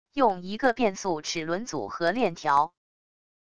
用一个变速齿轮组和链条wav音频